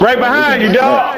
right behind you dawg Meme Sound Effect